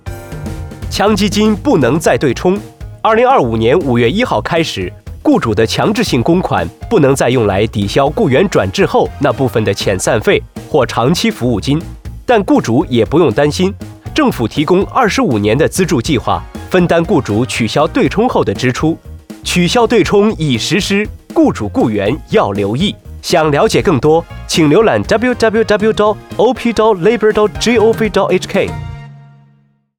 取消强积金「对冲」安排电台广播粤语 (8.33 MB) /普通话 (8.28 MB)